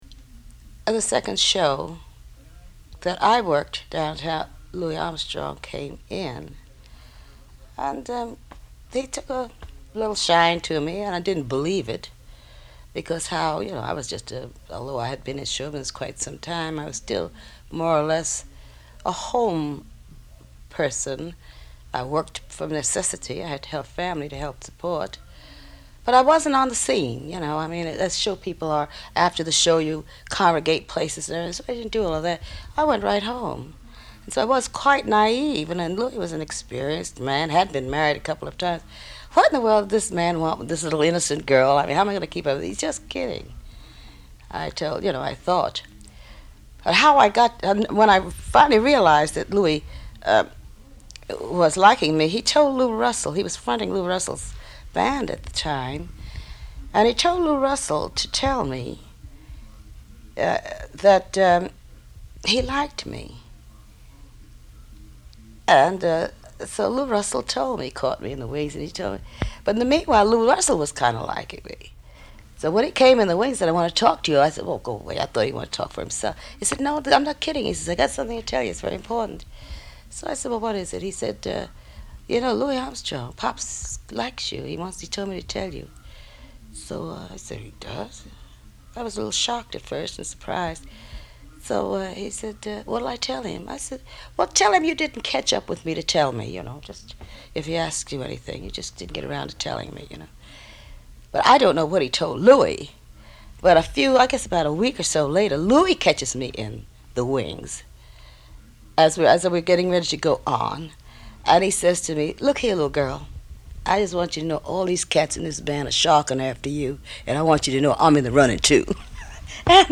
Click here to create a free account and listen to the rest of this interview on our Digital Collections site